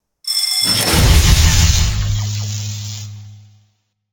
Blackout.ogg